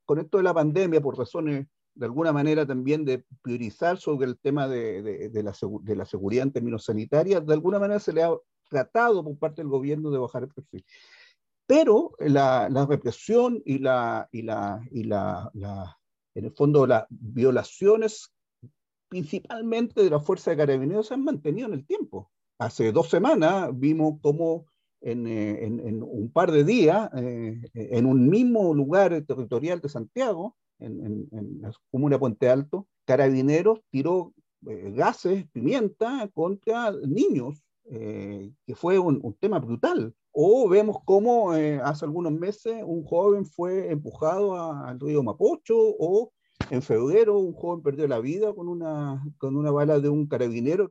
En conversación con Nuestra Pauta